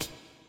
Closed Hats
CC - Twisted Hi Hat.wav